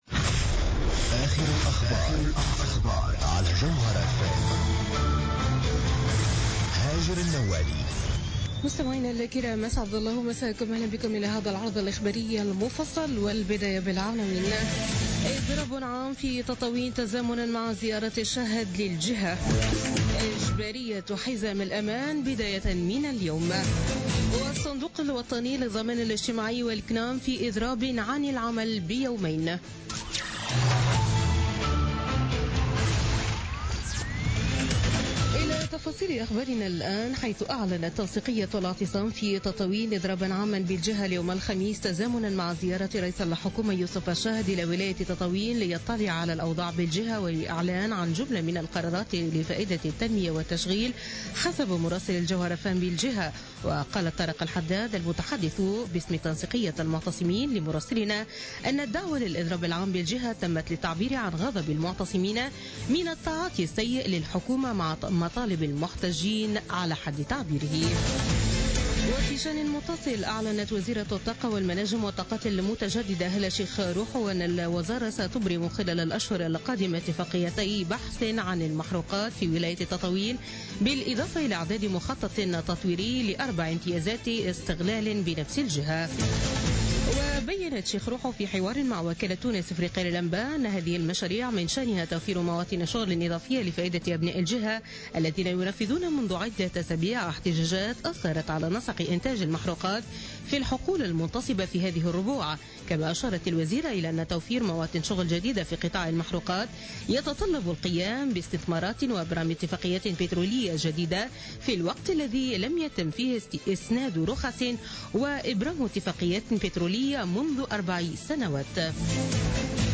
نشرة أخبار منتصف الليل ليوم الخميس 27 أفريل 2017